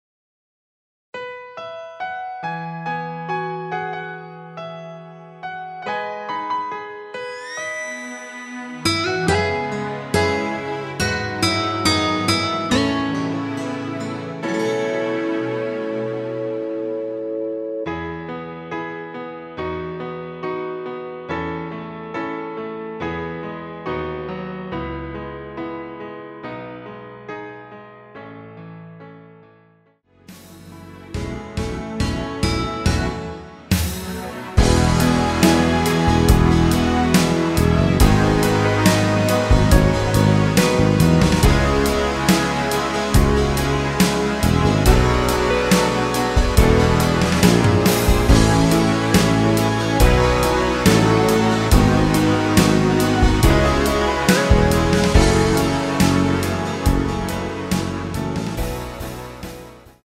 *부담없이즐기는 심플한 MR~
앞부분30초, 뒷부분30초씩 편집해서 올려 드리고 있습니다.
중간에 음이 끈어지고 다시 나오는 이유는
곡명 옆 (-1)은 반음 내림, (+1)은 반음 올림 입니다.